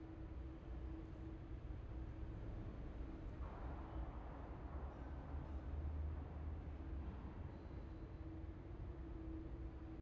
sfx-jfe-amb-loop-1.ogg